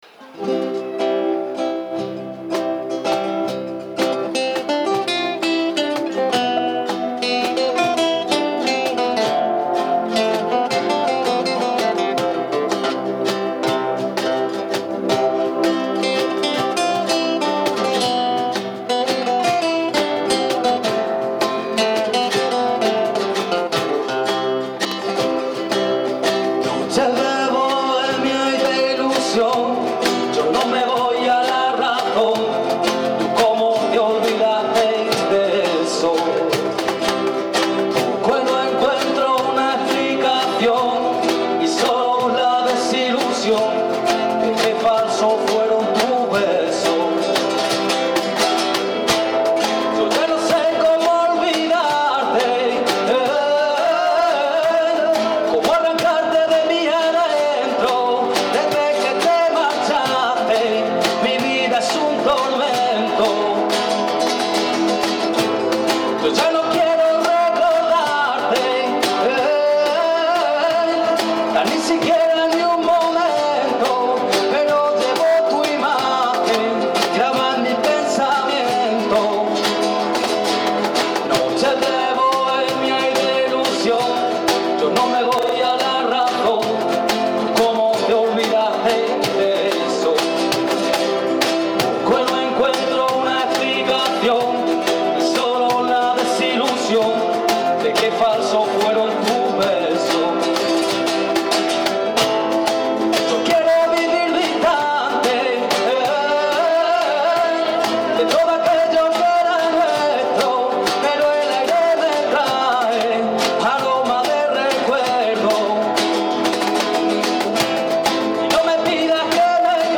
Gala 25 aniversario
Hubo actuaciones musicales a cargo de los alumnos del grupo, proyección de vídeos inéditos de antiguos alumnos, y también actuó el conjunto totanero "Doctor Tellini"